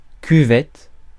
Prononciation
Prononciation France: IPA: /ky.vɛt/ Le mot recherché trouvé avec ces langues de source: français Traduction 1.